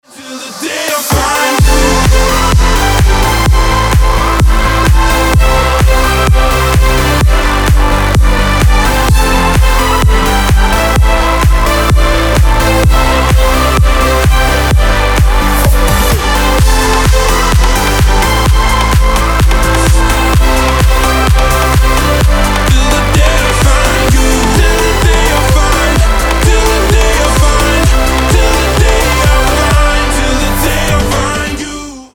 Стиль: future house